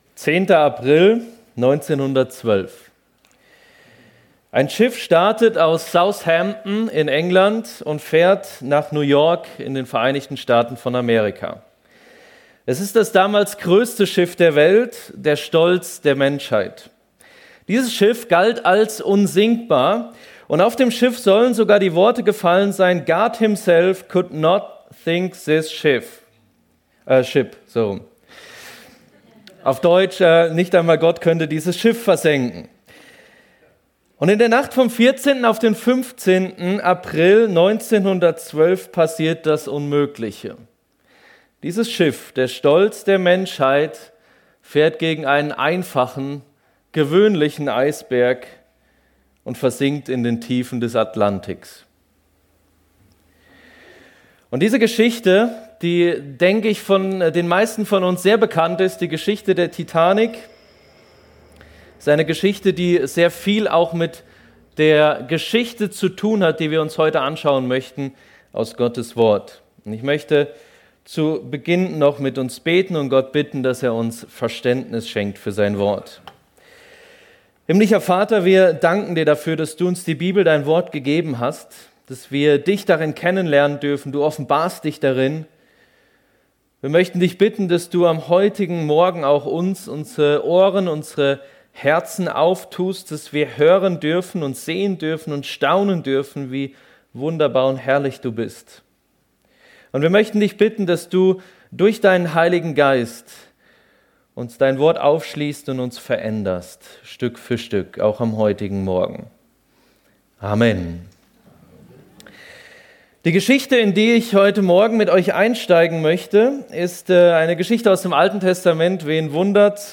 Turmbau zu Babel ~ FEG Sumiswald - Predigten Podcast